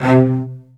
CELLOS.DN3-L.wav